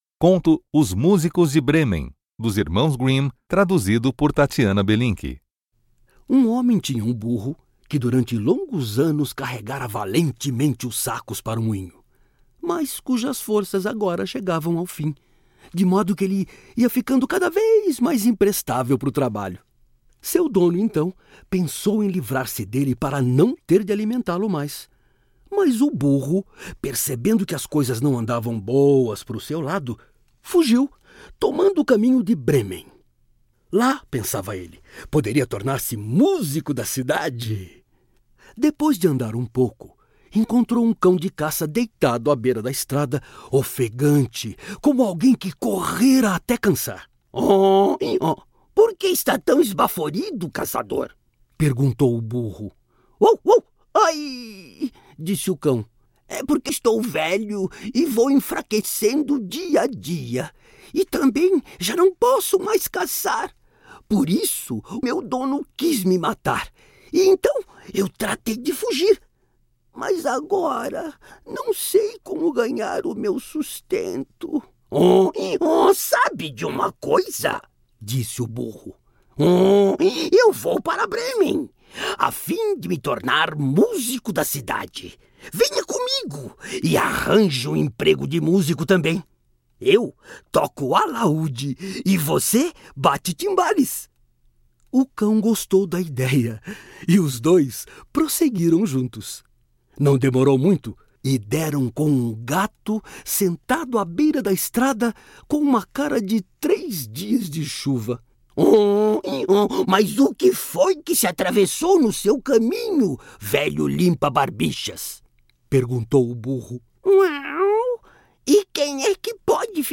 Conto "Os músicos de Brêmen"